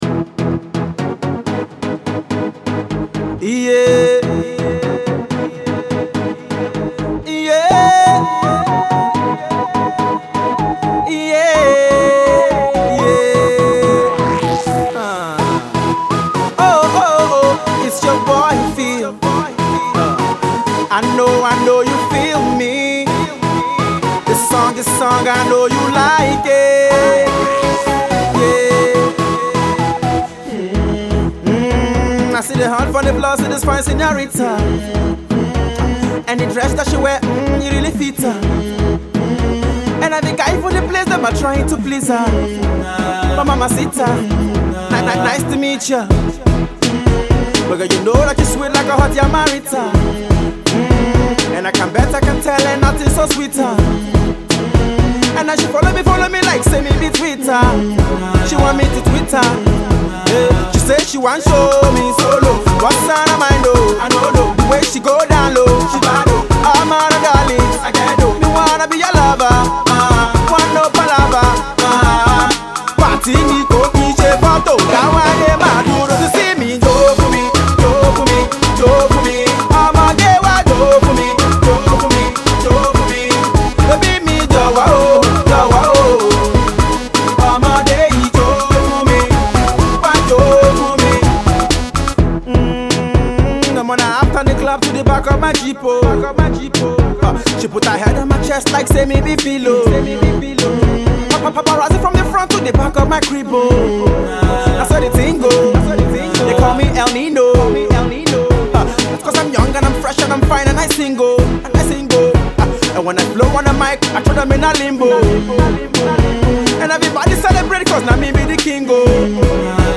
very catchy club-banging follow-up single